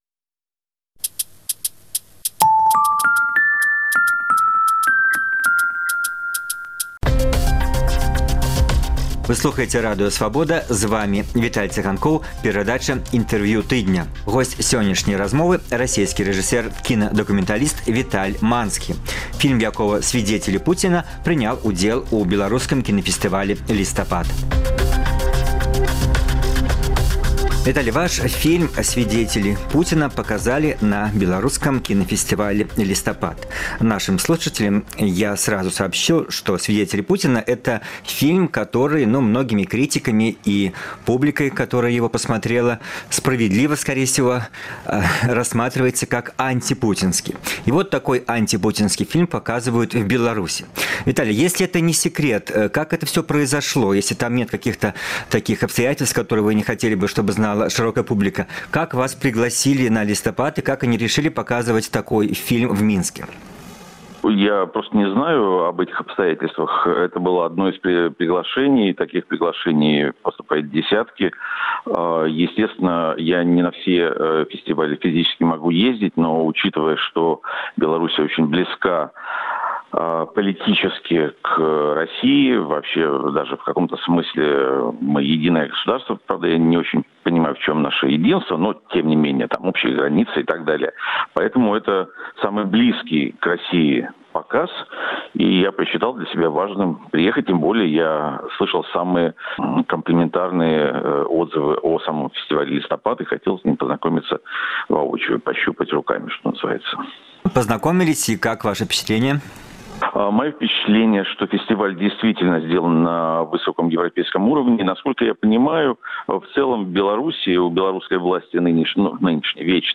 Старажытнае і нават містычнае беларускае слова «хапун» менчукі на вуліцы тлумачаць па-рознаму.